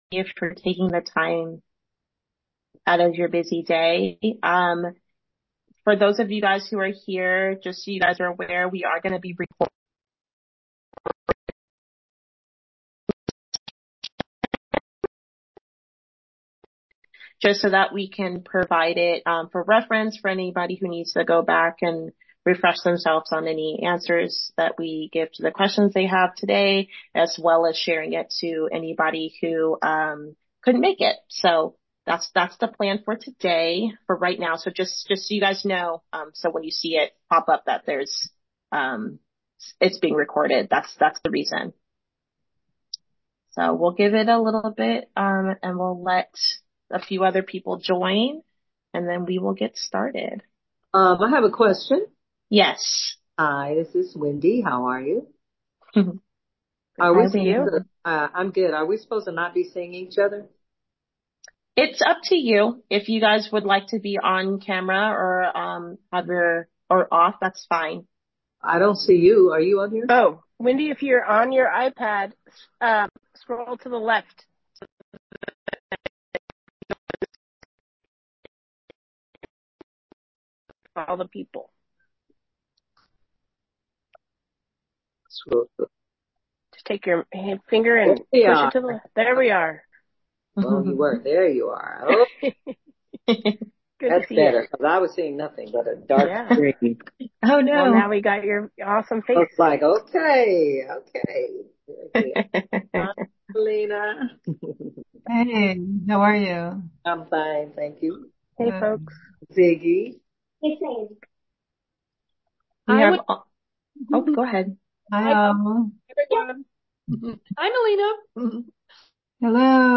2025 Grant: Q&A Session
Additional-QA-for-2025-Arts-United-Grant.mp3